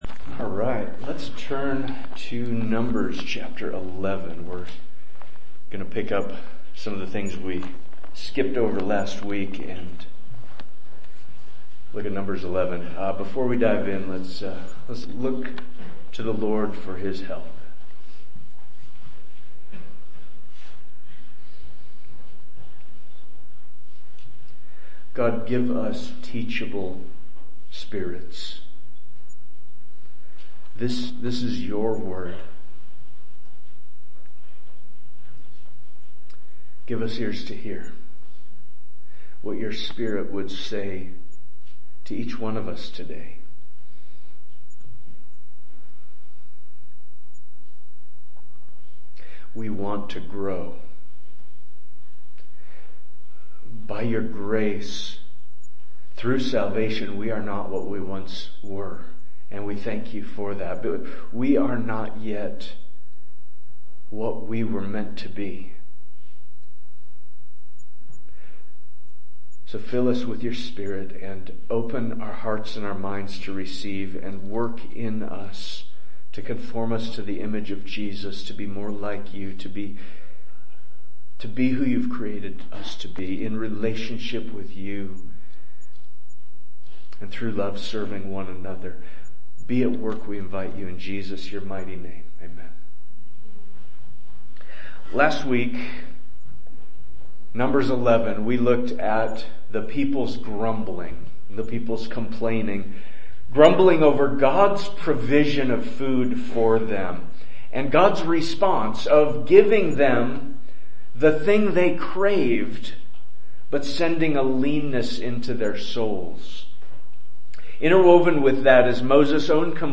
Sermon Manuscript